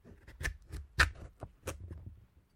Звуки тыквы
Здесь собраны разнообразные аудиоэффекты: от мягкого постукивания по кожуре до сочного хруста при разрезании.
Снимаем кожуру